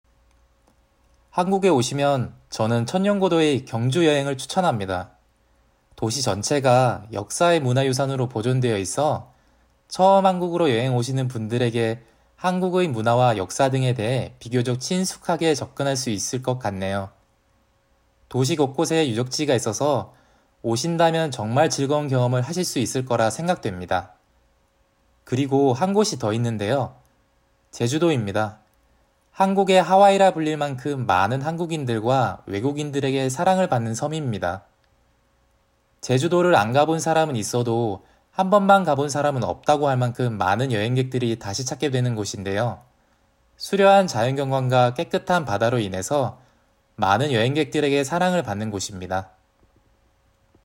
(He also recorded his responses at a normal speaking speed and tone.)